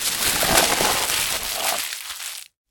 vending1.ogg